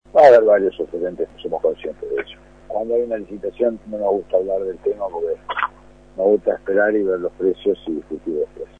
Miguel Brechner en 810 VIVO